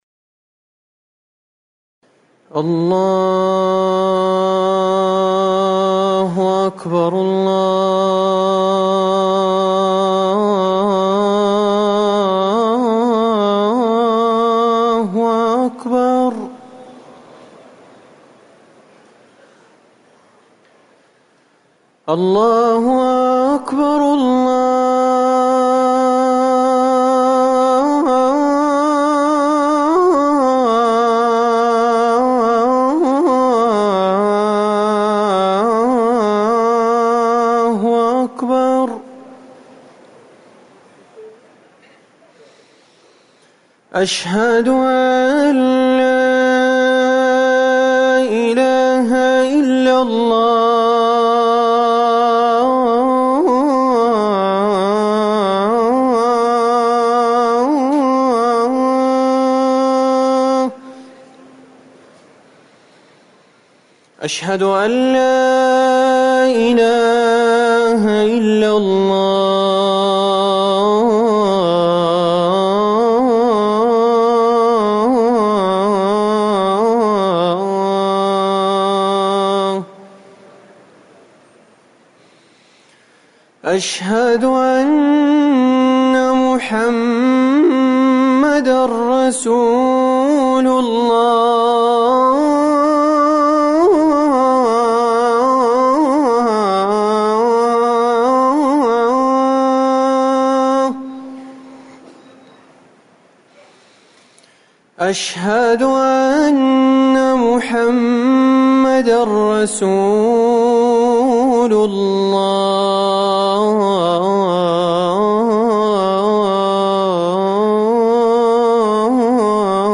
أذان المغرب - الموقع الرسمي لرئاسة الشؤون الدينية بالمسجد النبوي والمسجد الحرام
تاريخ النشر ١٤ محرم ١٤٤١ هـ المكان: المسجد النبوي الشيخ